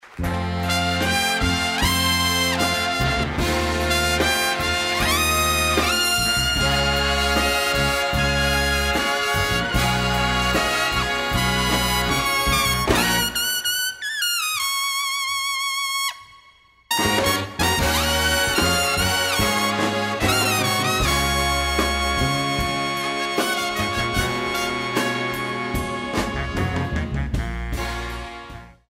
the applause and whistles and clinking of glasses.
the fiery trombone